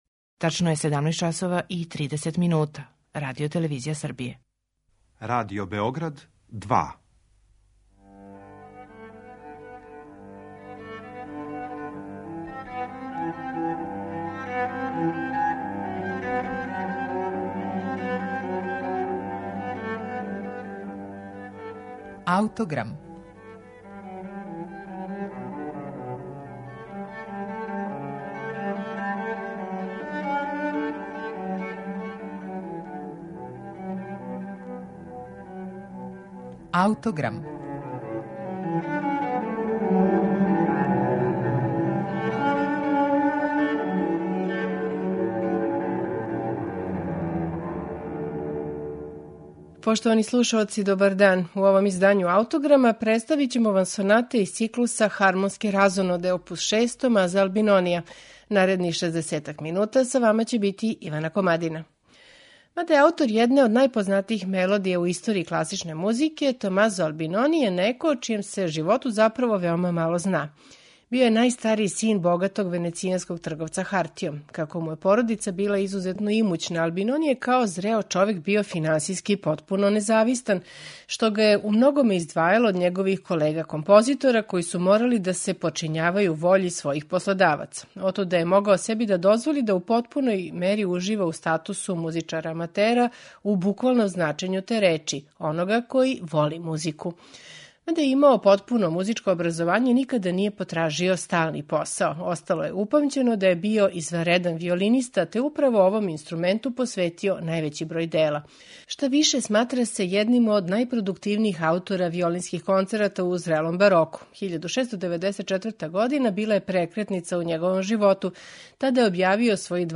на оригиналним инструментима епохе
виолина
виолончело
оргуље